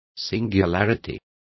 Complete with pronunciation of the translation of singularity.